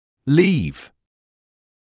[liːv][лиːв]оставлять; уезжать, покидать